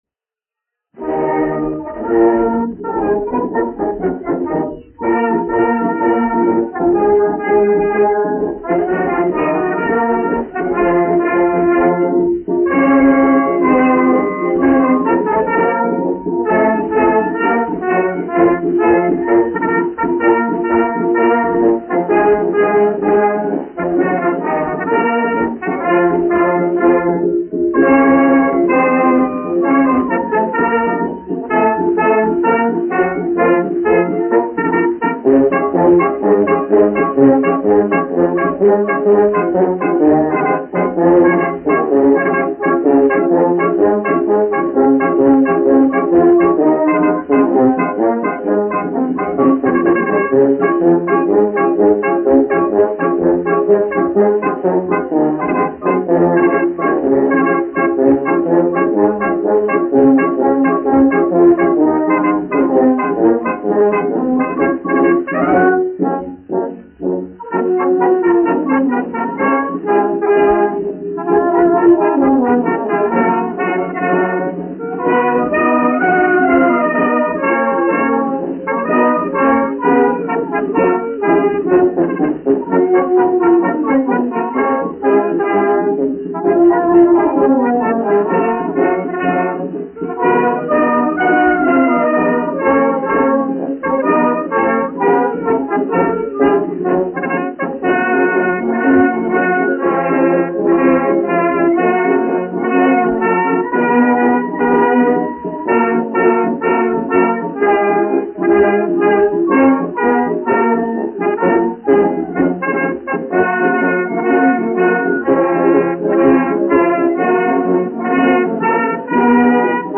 1 skpl. : analogs, 78 apgr/min, mono ; 25 cm
Marši
Pūtēju orķestra mūzika